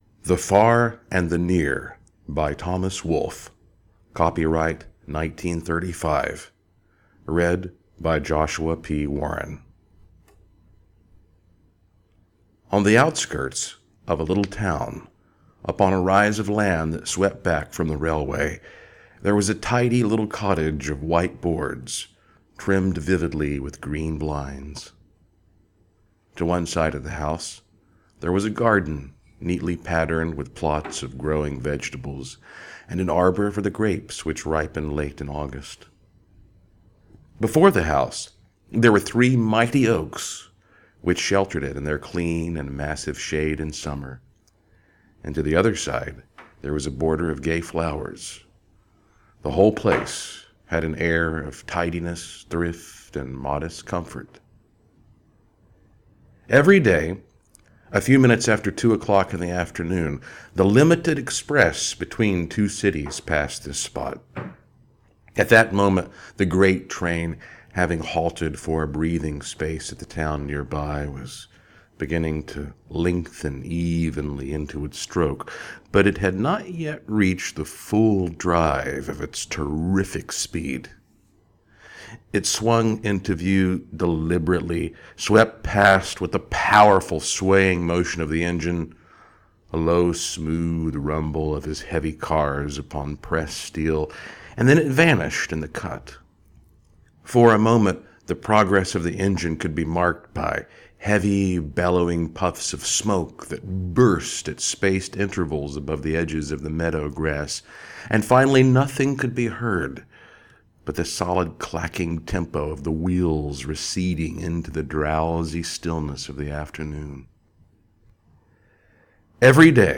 Reads His Favorite Short Story: The Far and the Near By Thomas Wolfe Written in 1935 To download